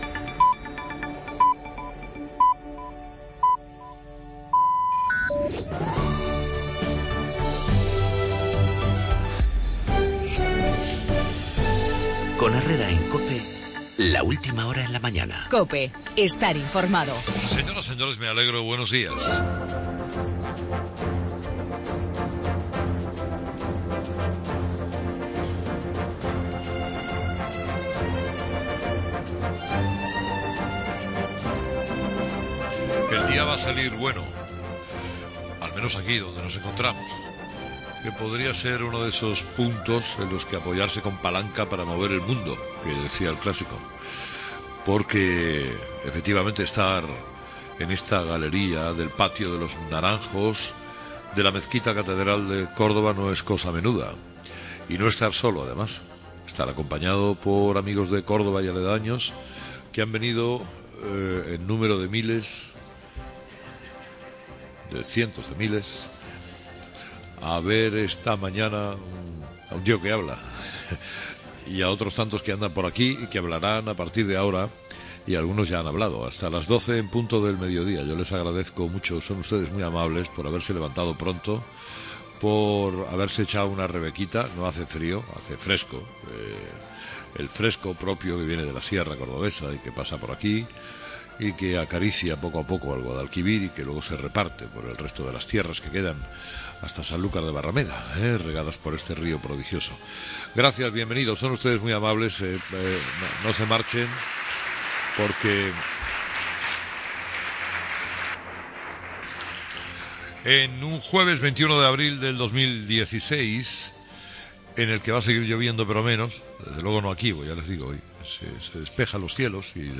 El programa de Carlos Herrera, con más de 2 millones de oyentes, se emitió esta semana desde el Patio de los Naranjos.
Estaba tan a gusto el hombre, que se marcó un pasodoble que supo a flamenco y a fiesta.